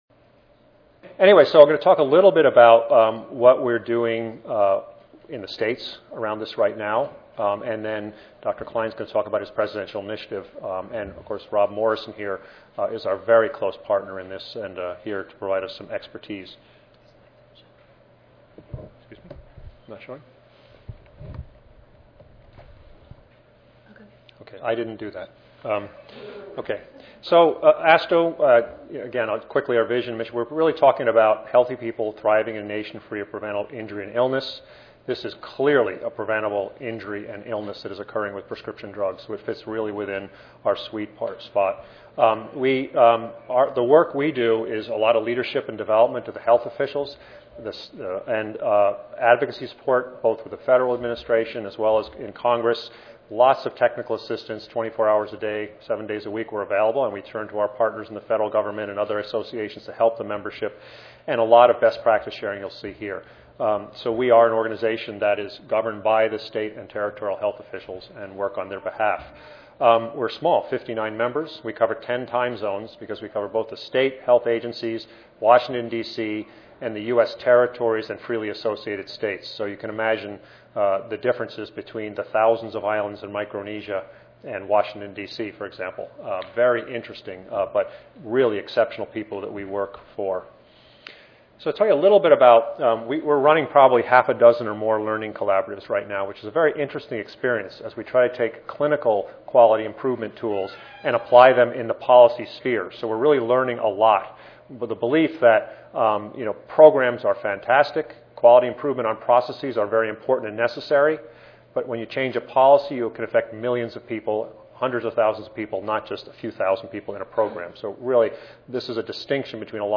141st APHA Annual Meeting and Exposition (November 2 - November 6, 2013): State learning community model to address prescription drug overdose misuse, abuse and diversion